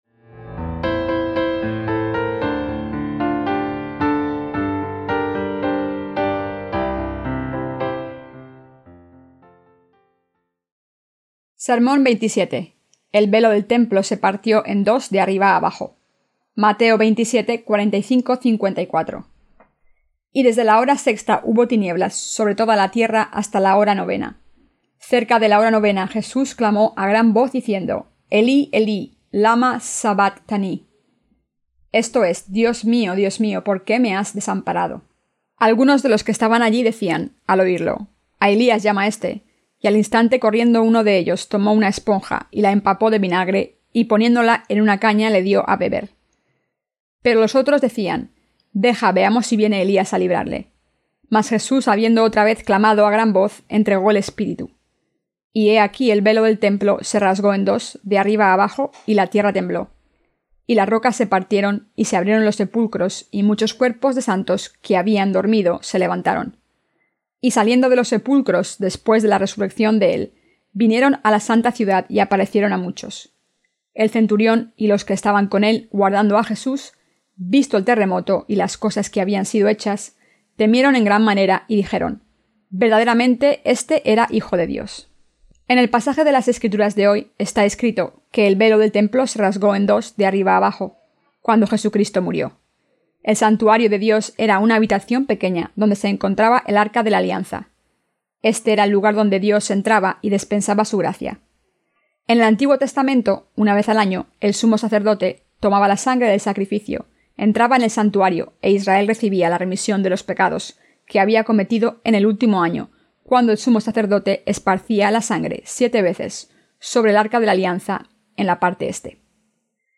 SERMONES SOBRE EL EVANGELIO DE MATEO (VI)-¿A QUIÉN SE LE PRESENTA LA MEJOR VIDA?